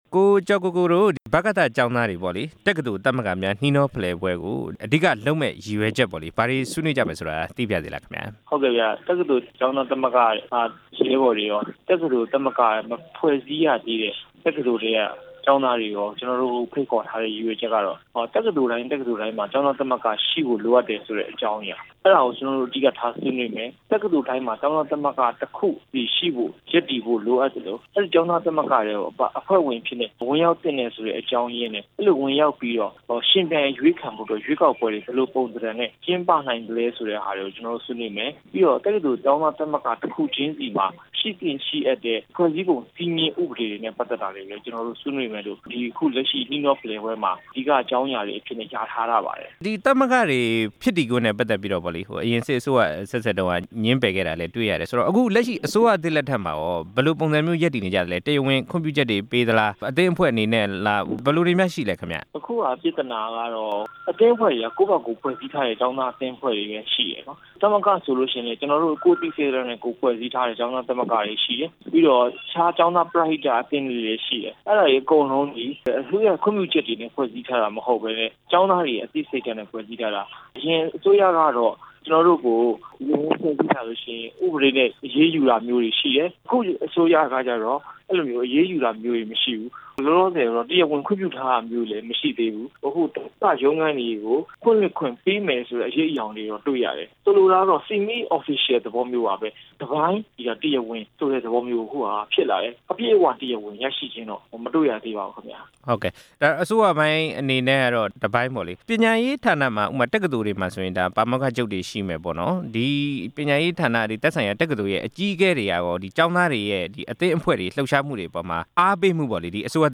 မေးမြန်းချက်